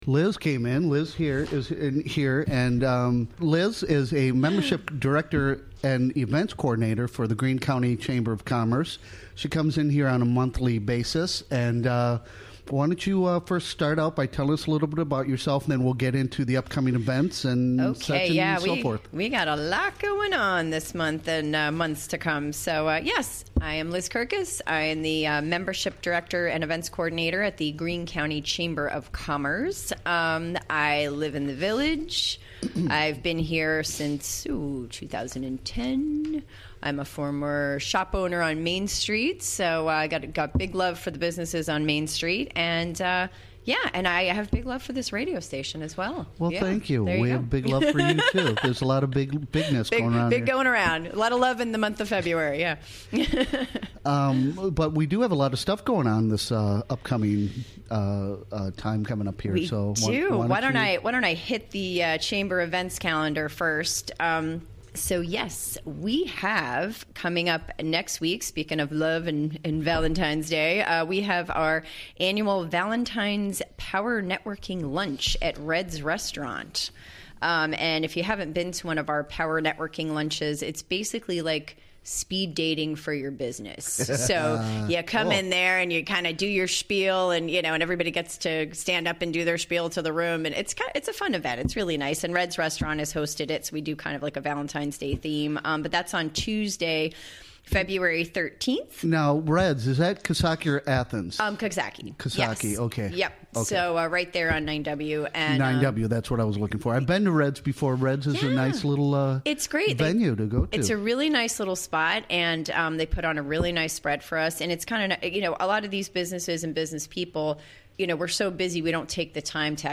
Recorded during the WGXC Morning Show of Tuesday, Feb. 6, 2018.